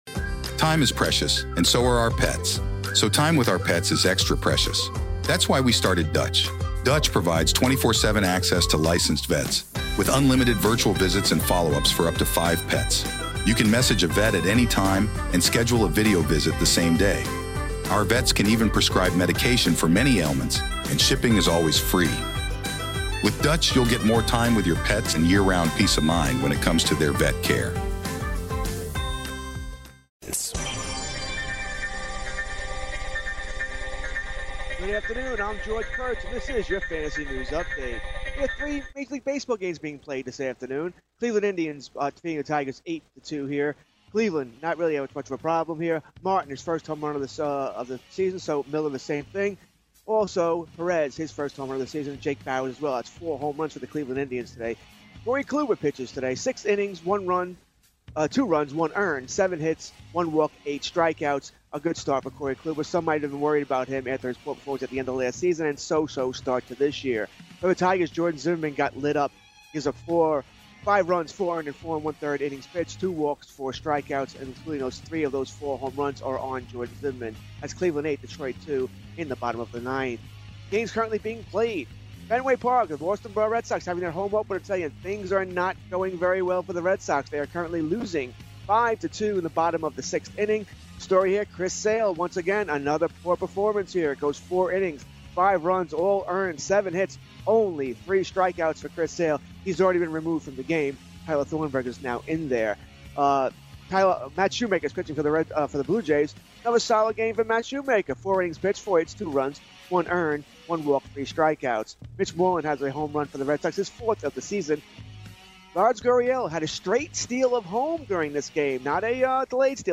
calls in from Las Vegas